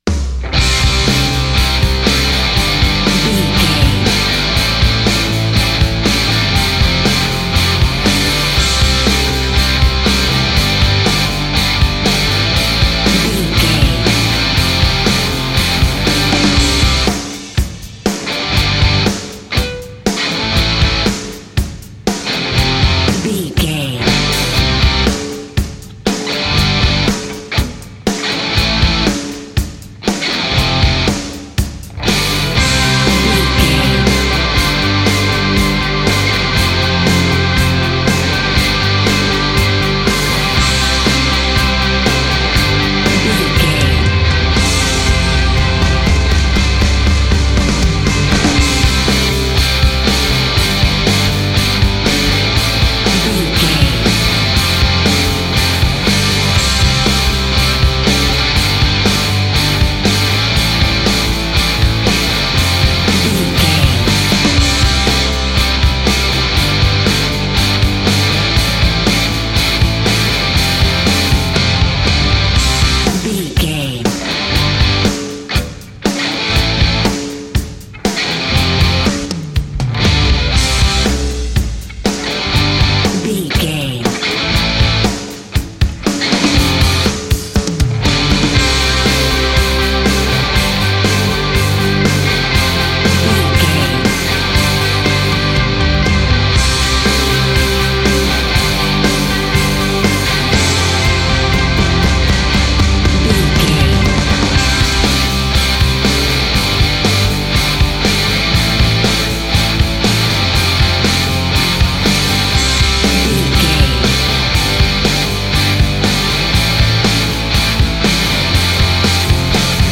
Ionian/Major
drums
electric guitar
bass guitar
pop rock
hard rock
lead guitar
aggressive
energetic
intense
nu metal
alternative metal